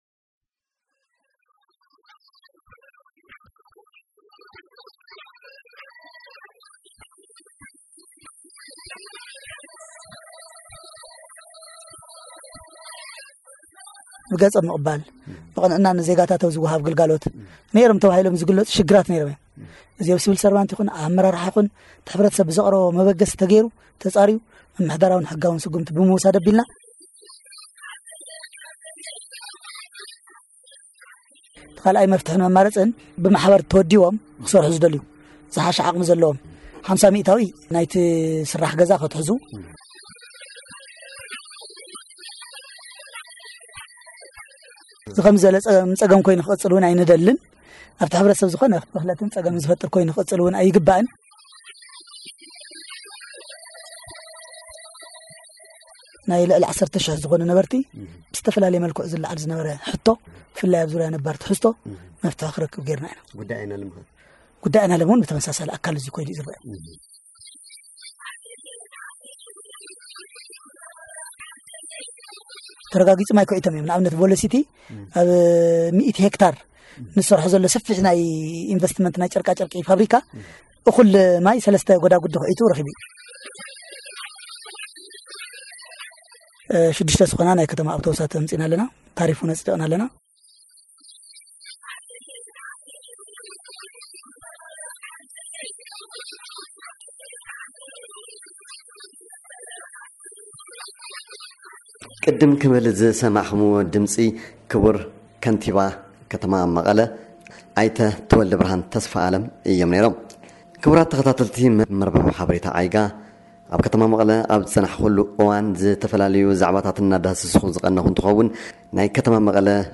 Interview-with-Mayor-Mekelle-TeweledeBerhan.MP3